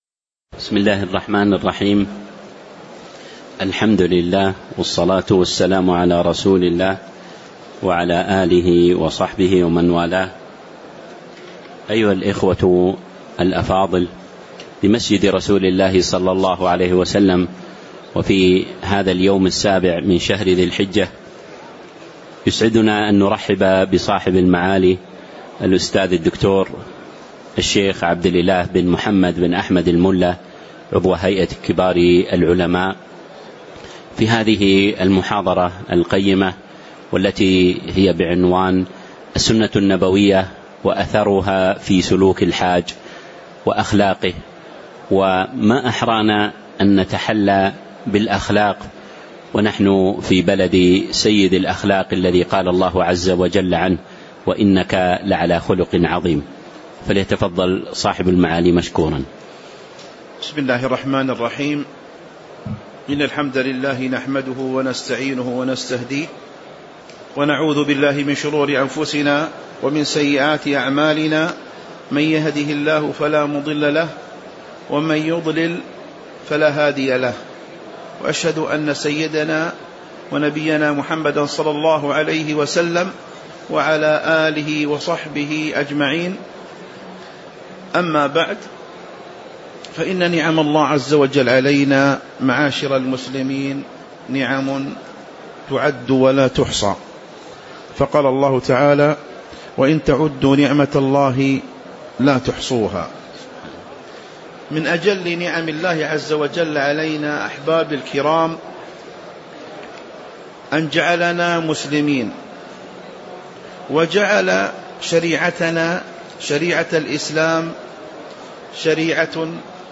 تاريخ النشر ٧ ذو الحجة ١٤٤٦ هـ المكان: المسجد النبوي الشيخ